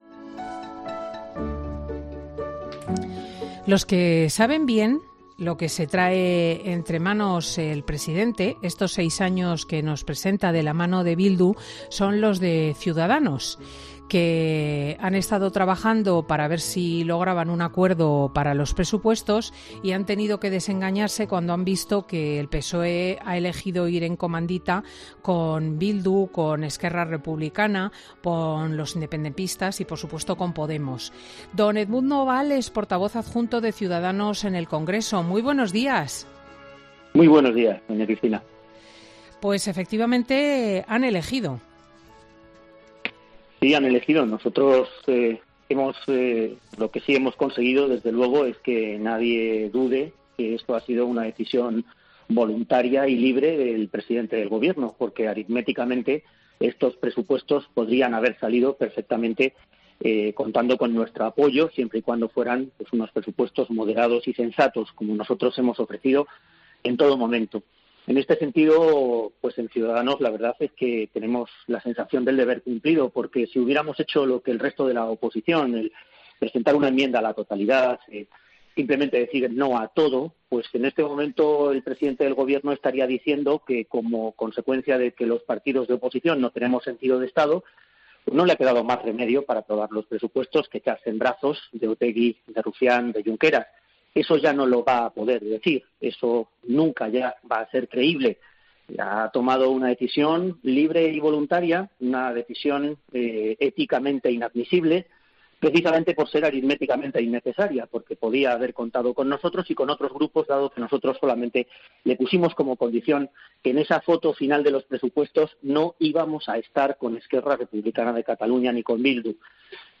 “ Aquí ha tenido que ver que la proximidad de las elecciones de Cataluña y ese objetivo indisimulado de tripartito en el que le den el gobierno de la Generalitat a ERC”, comentaba el diputado a Cristina López Schlichting.